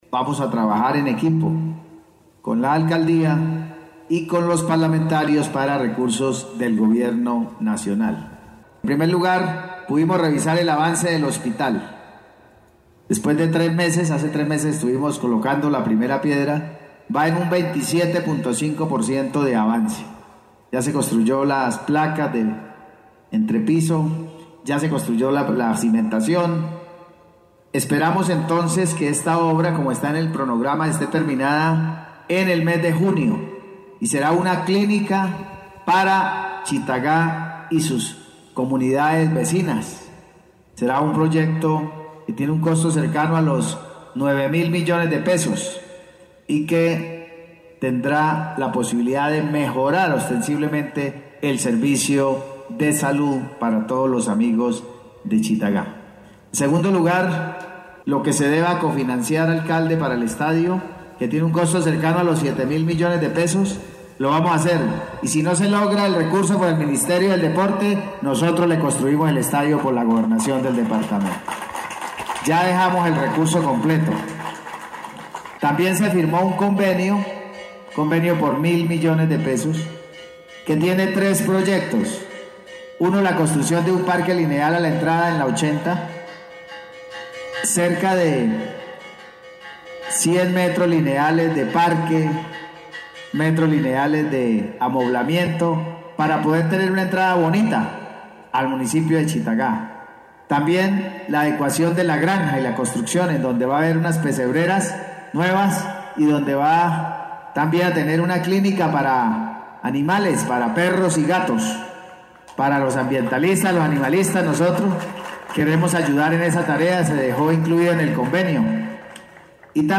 Audio-del-Gobernador-William-Villamizar-1.mp3